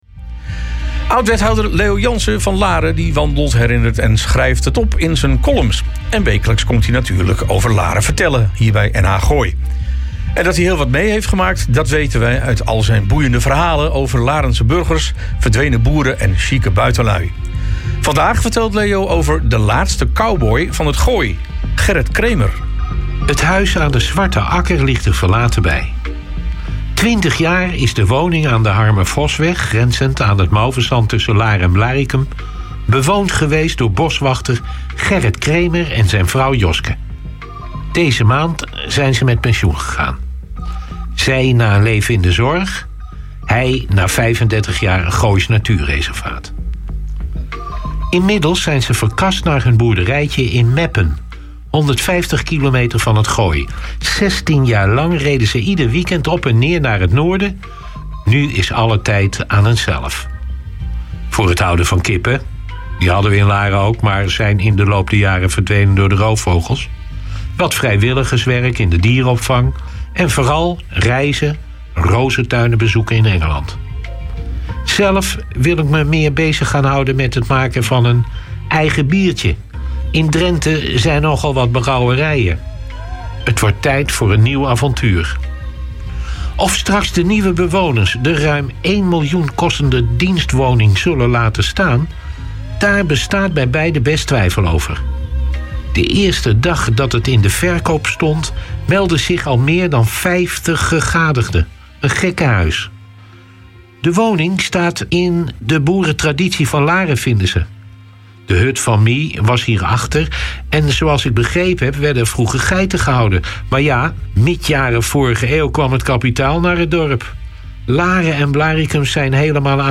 Oud-wethouder Leo Janssen van Laren wandelt, herinnert en schrijft het op in z'n columns. En wekelijks komt hij over Laren vertellen bij NH Gooi.